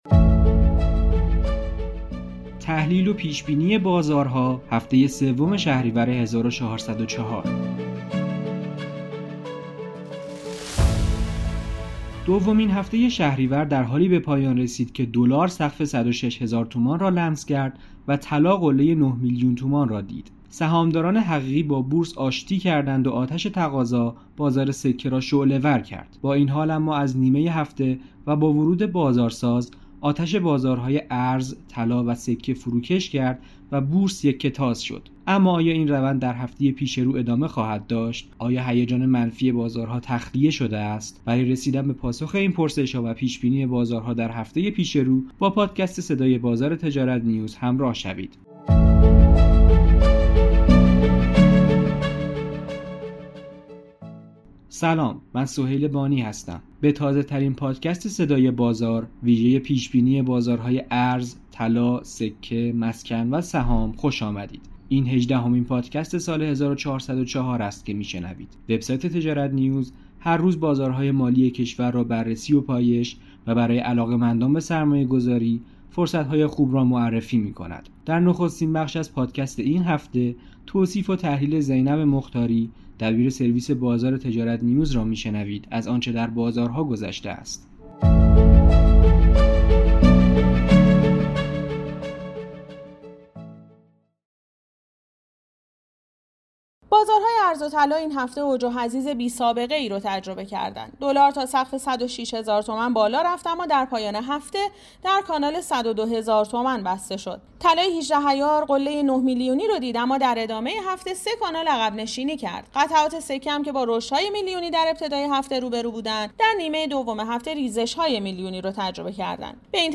به گزارش تجارت نیوز، به تازه‌ترین پادکست صدای بازار ویژه پیش بینی بازارهای ارز، طلا، سکه، مسکن و سهام خوش آمدید.